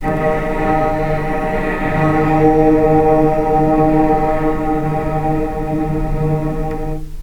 vc_sp-D#3-pp.AIF